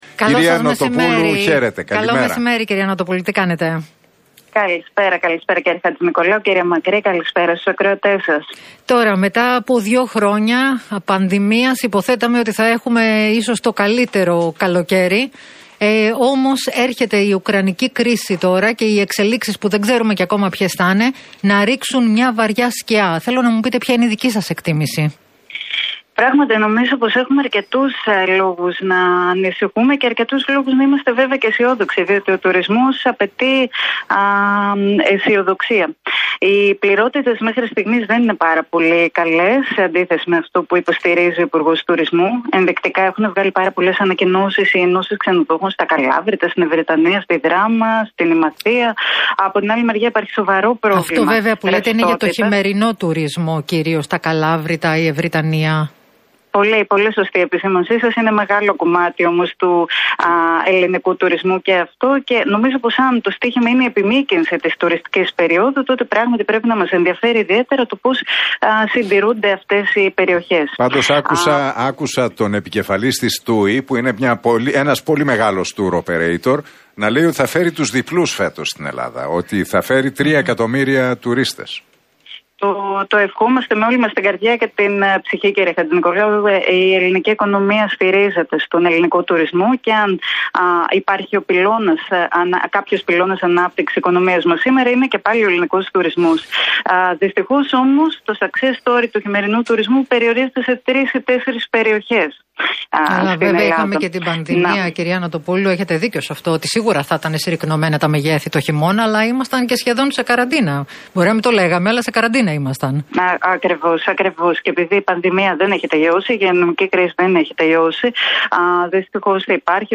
Η βουλευτής και τομεάρχης Τουρισμού του ΣΥΡΙΖΑ Κατερίνα Νοτοπούλου, μιλώντας στον Νίκο Χατζηνικολάου